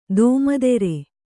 ♪ dōmadere